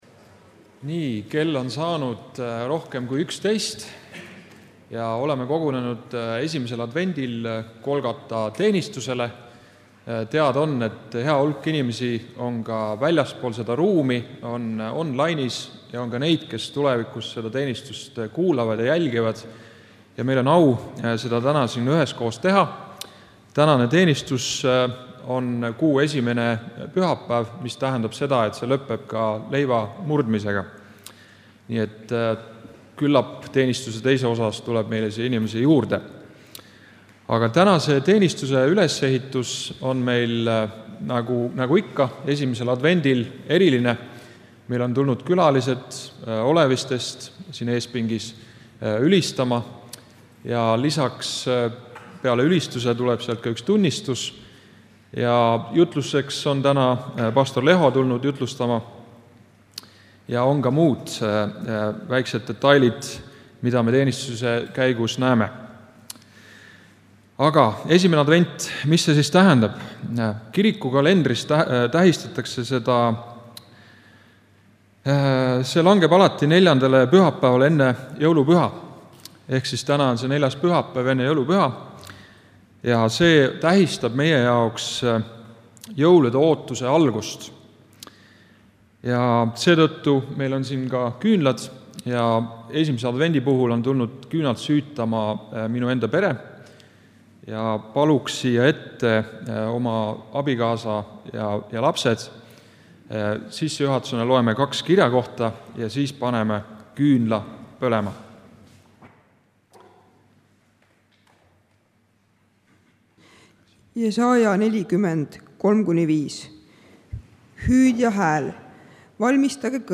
Kõik jutlused
Muusika: Oleviste noortebänd